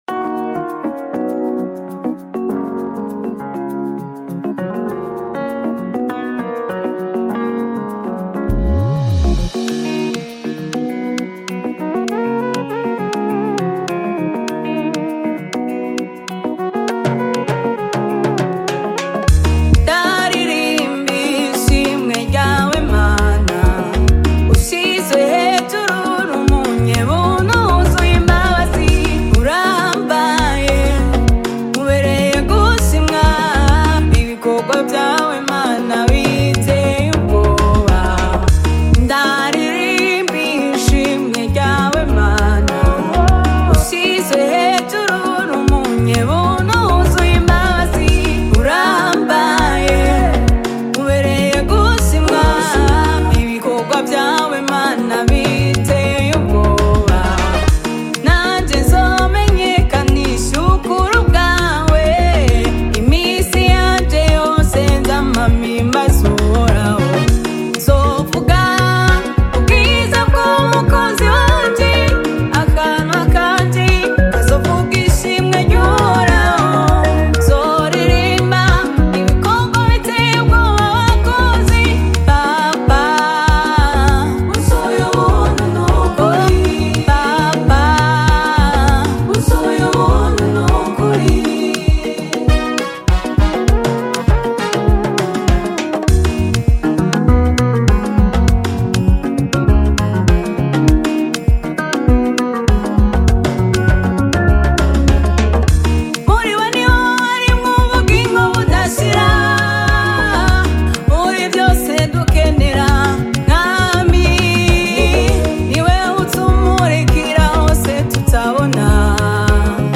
Umuririmvyikazi aririmba indirimbo zogutazira Imana